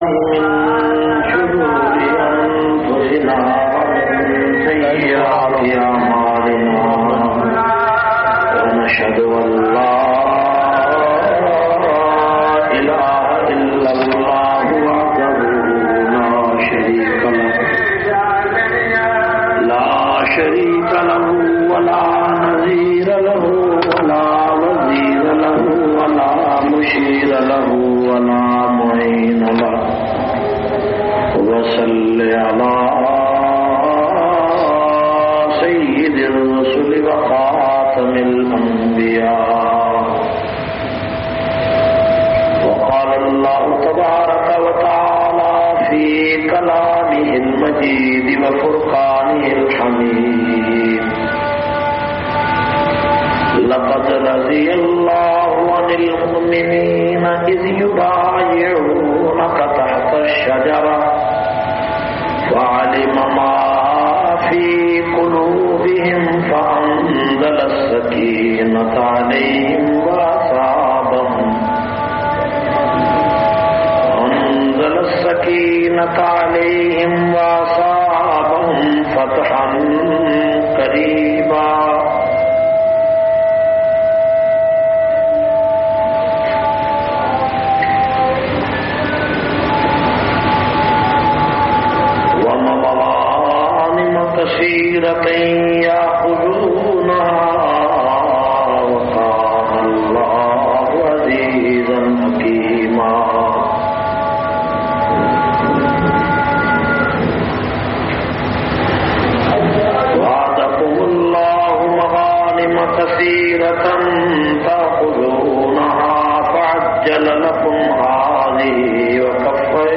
524- Shan e Usman Ghani Jumma khutba Jamia Masjid Muhammadia Samandri Faisalabad.mp3